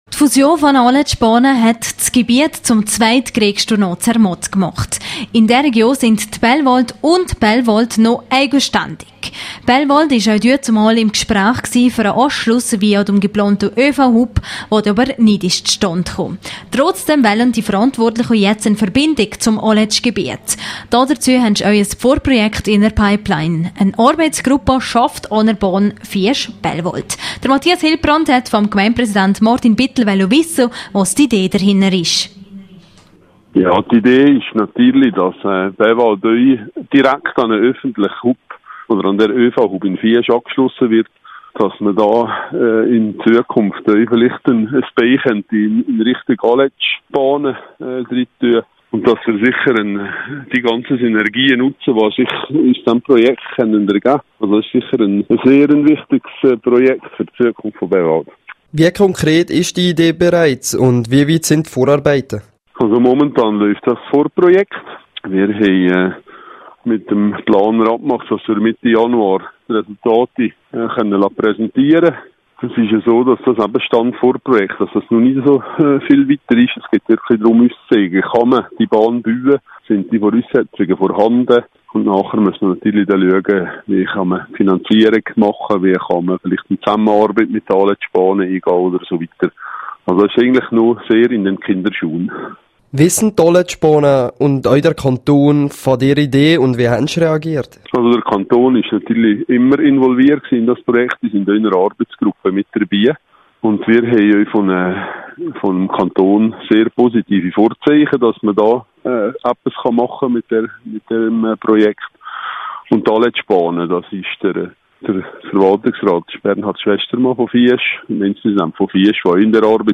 Durch diese Verbindung wollen die Verantwortlichen auch das eigene Skigebiet aufwerten./hm Interview mit Martin Bittel, Gemeindepräsident Bellwald.